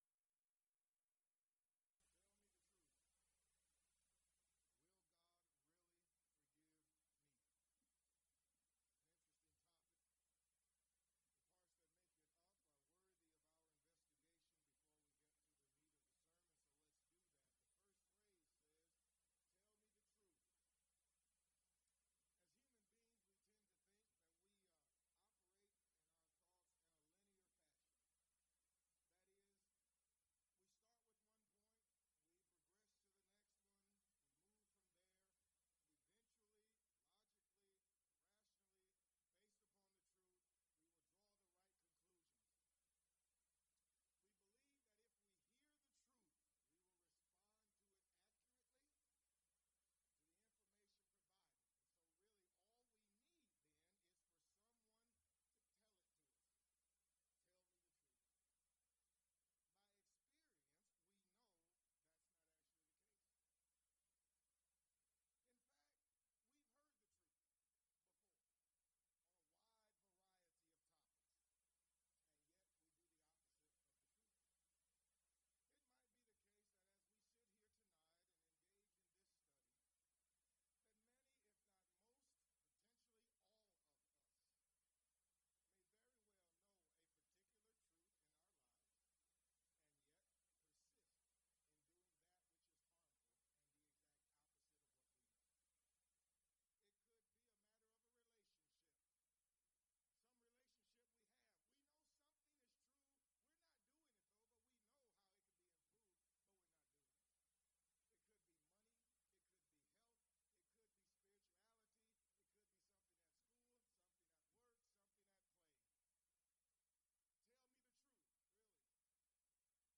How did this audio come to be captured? Event: 2017 Focal Point Theme/Title: Preacher's Workshop